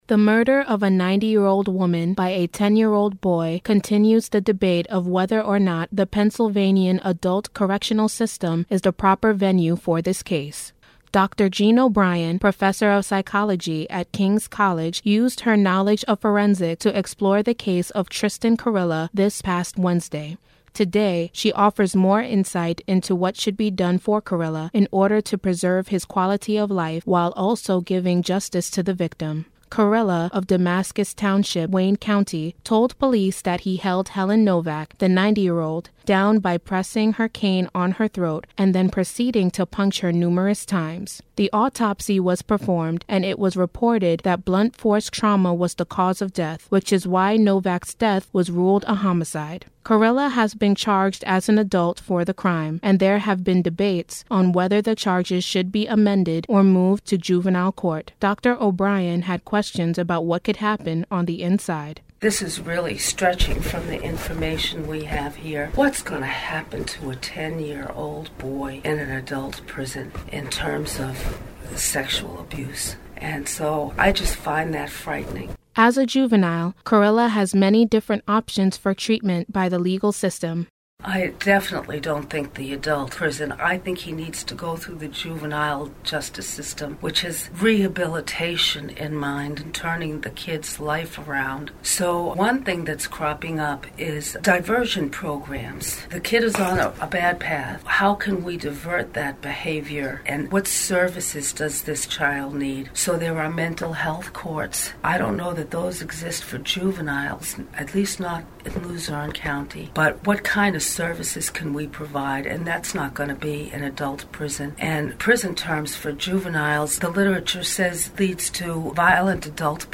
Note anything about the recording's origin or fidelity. WRKC is the student radio station of King’s College.